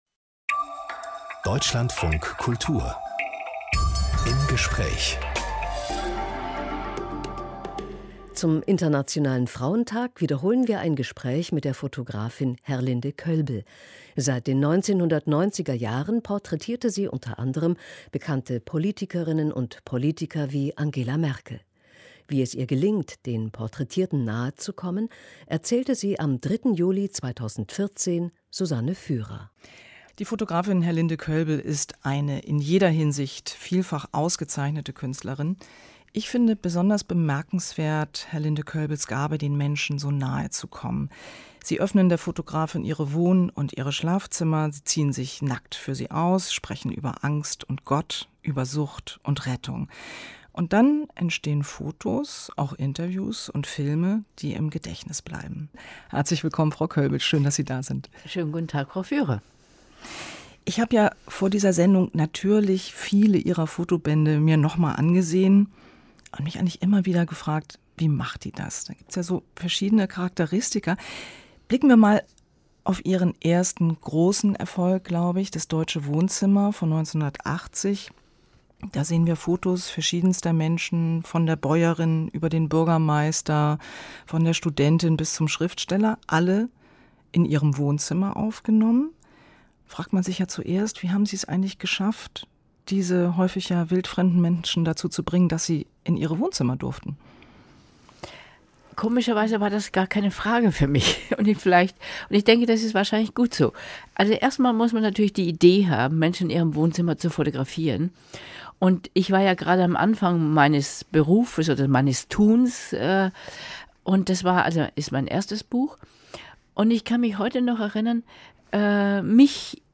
Zum Internationalen Frauentag wiederholen wir ein Gespräch mit der Fotografin Herlinde Koelbl. Seit den 90er-Jahren porträtierte sie u.a. bekannte Politikerinnen und Politiker wie Angela Merkel.